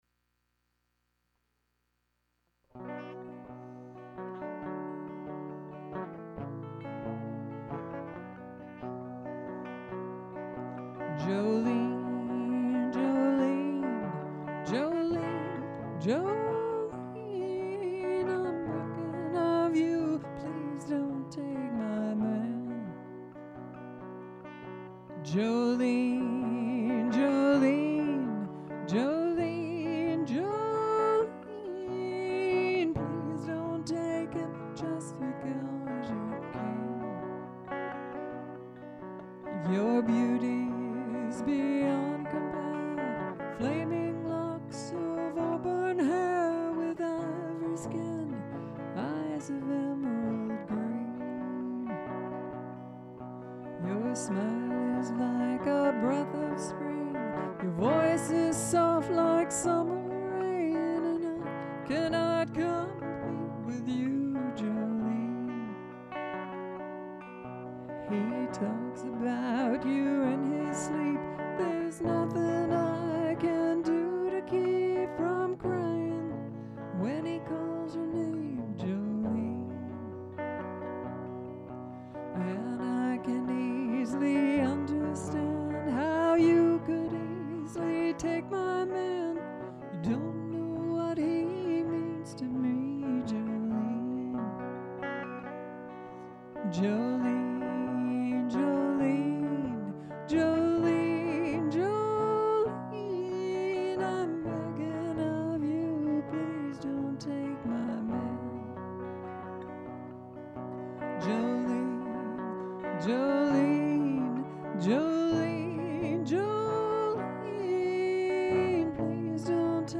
Guitar. Stero